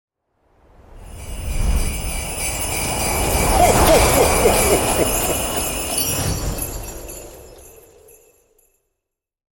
Santa Claus Passing!!! Christmas Sound sound effects free download